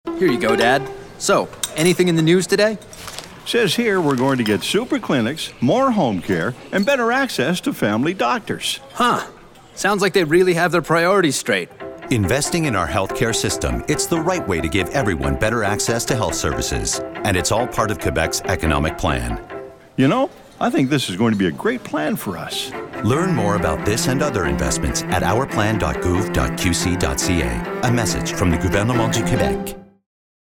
Publicité (Ministère des finances) - ANG